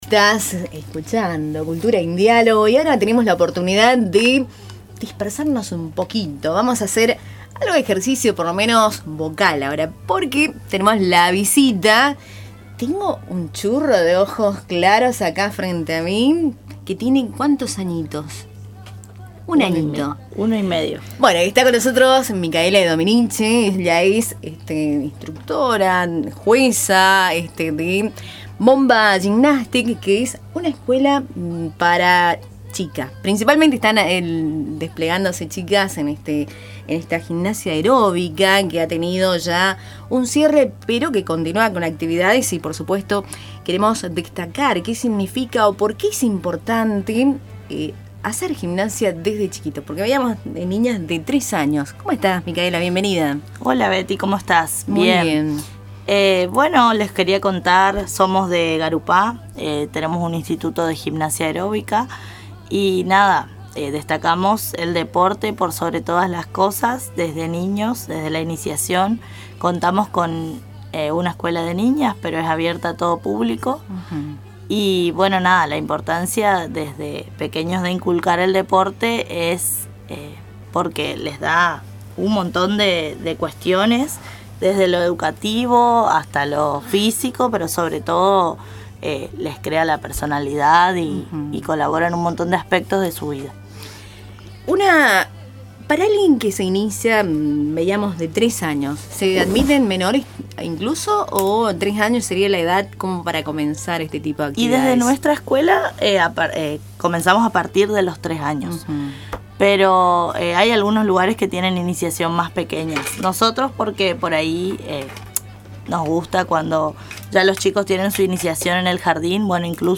visitó el estudio de Tupambaé